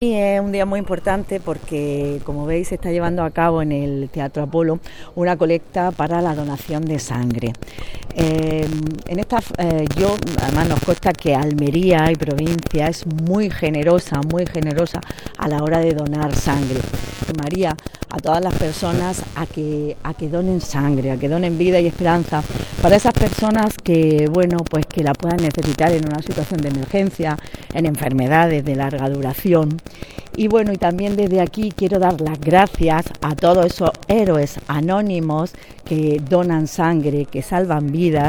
19-12_donacion_navidad_diputada.mp3